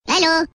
Nada notifikasi Minion Hello
Kategori: Nada dering